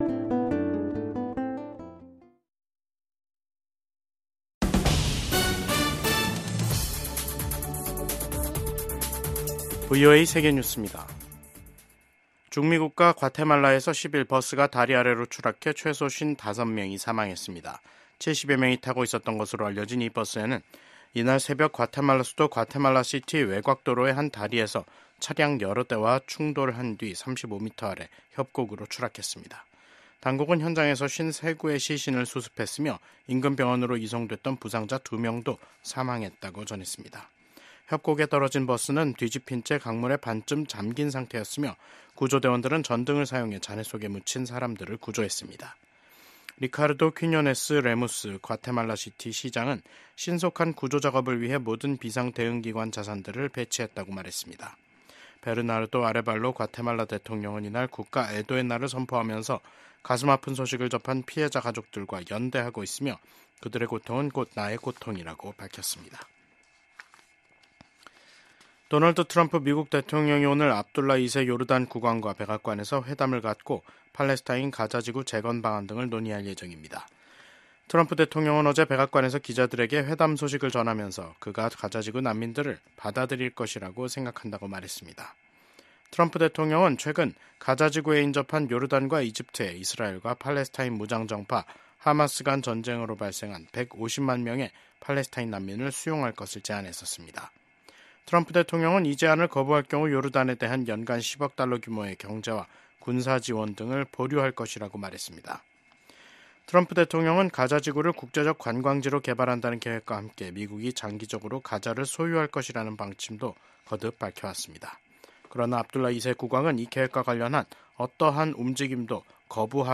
VOA 한국어 간판 뉴스 프로그램 '뉴스 투데이', 2025년 2월 11일 3부 방송입니다. 도널드 트럼프 미국 행정부 출범 이후 대미 담화 빈도를 크게 늘린 북한이 이번엔 미국의 원자력 추진 잠수함(SSN)의 부산 입항을 비난하는 담화를 냈습니다. 미국의 한반도 전문가들은 지난주 미일 정상회담이 북한 비핵화와 미한일 3국 공조를 강조한 것에 주목하며 한반도와 역내 평화와 안보에 기여할 것으로 기대했습니다.